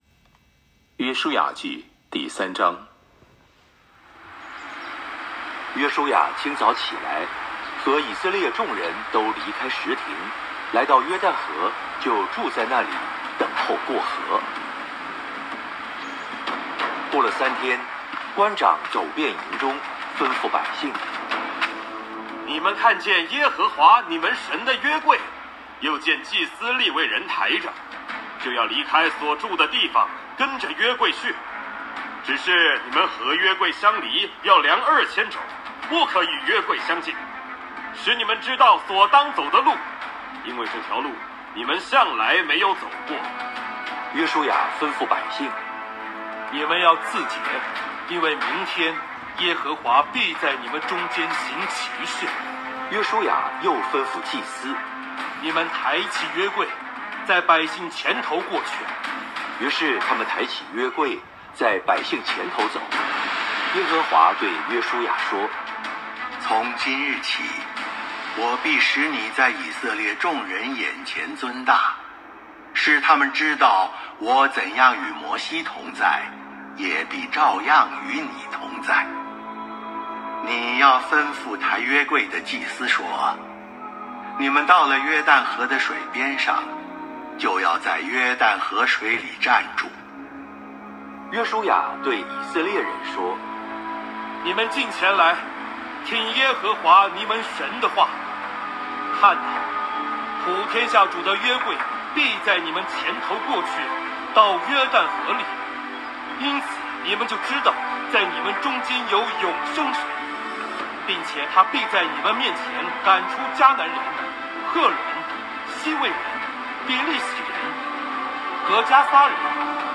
书03（经文-国）.m4a